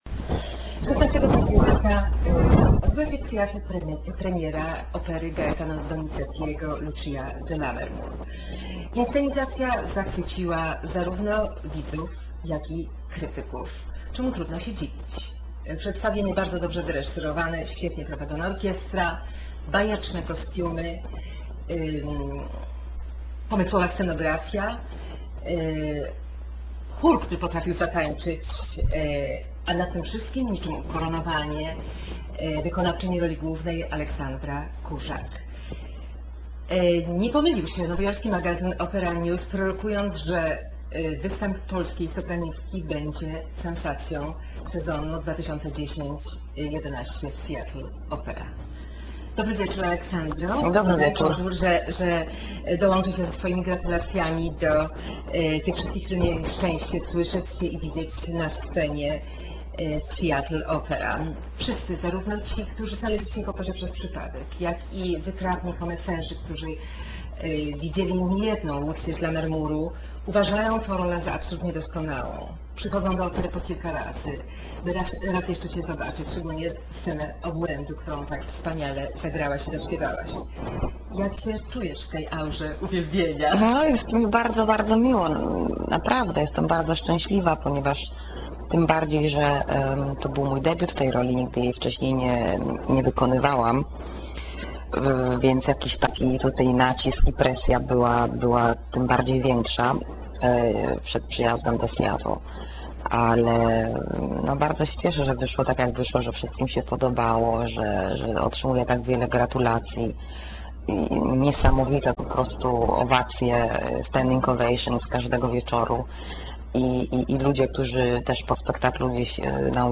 Pogawedka z diwa: Aleksandra Kurzak w Seattle.